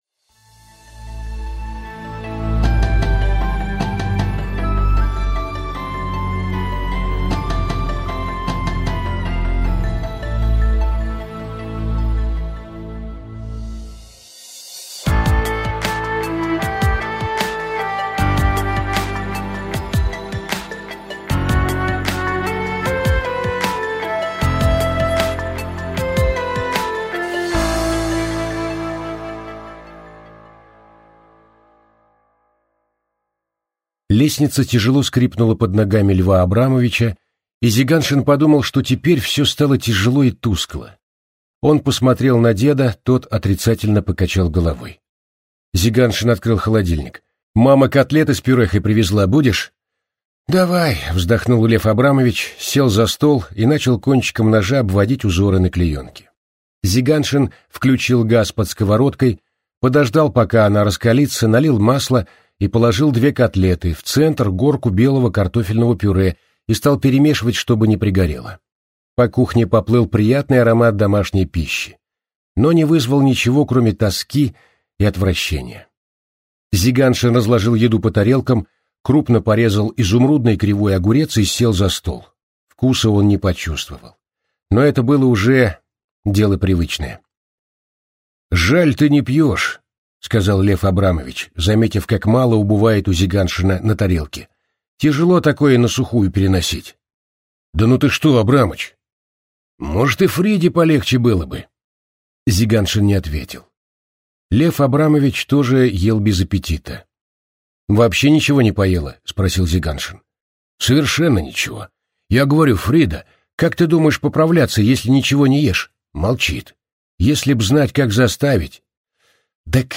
Аудиокнига Врачебная ошибка - купить, скачать и слушать онлайн | КнигоПоиск